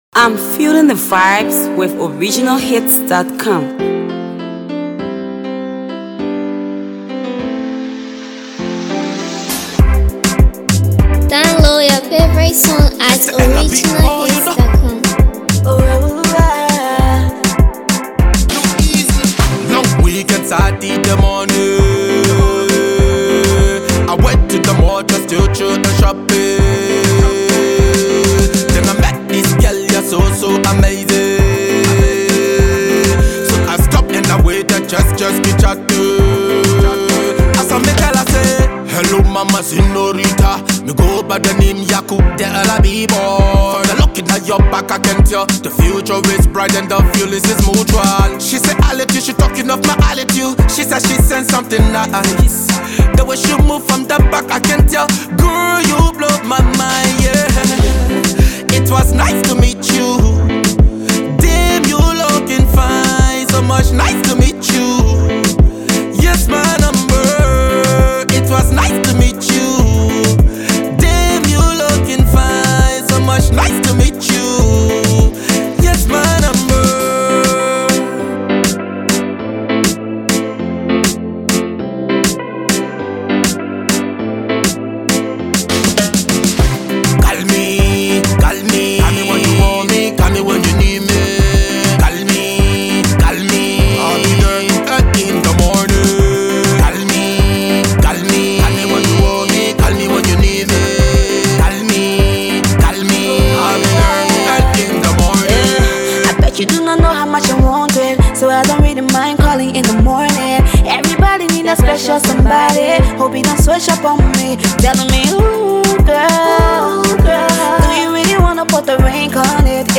It’s a certified studio effort.